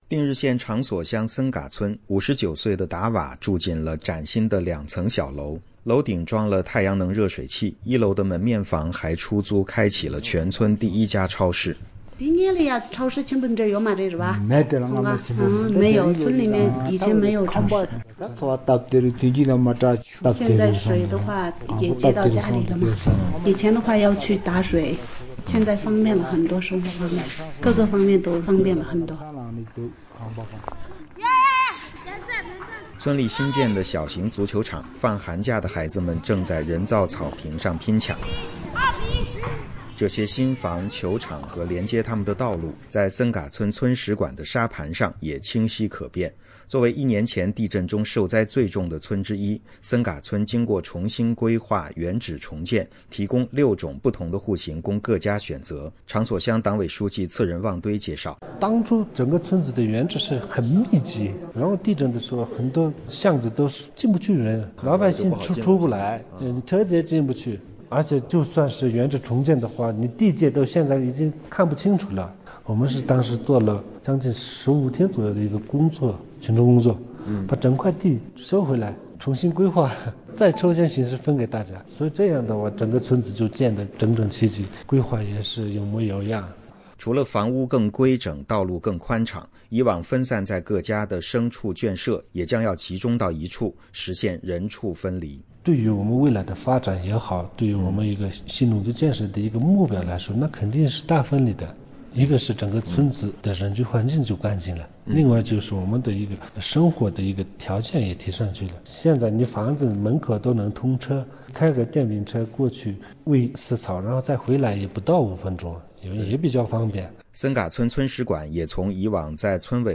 据中央广播电视总台中国之声《新闻纵横》报道，去年的今天，西藏日喀则市定日县发生6.8级地震。